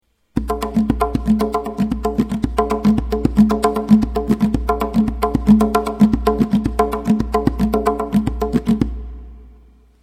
Tonbak Notations
The next rhythm is in 4/4. It is introduced step by step, starting simple and adding more and more notes:
and now we substitute the last ton: